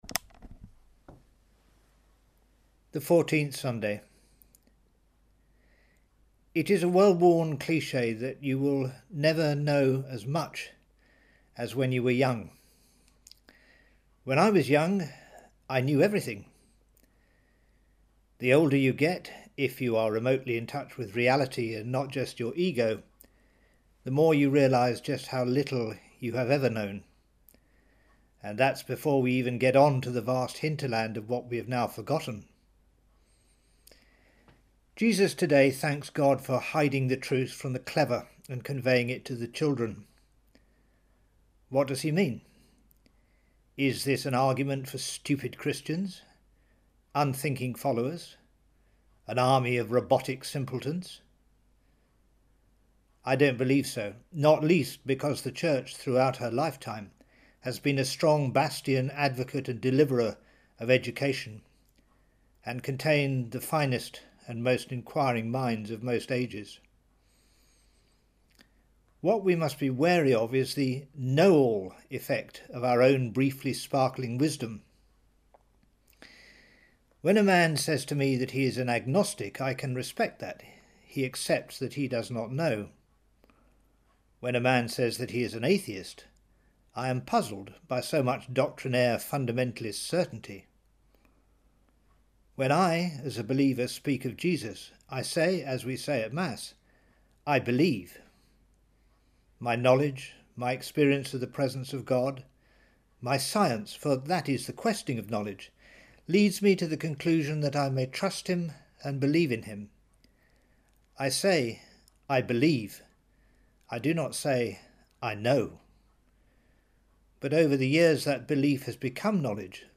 A Catholic homily